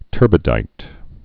(tûrbĭ-dīt)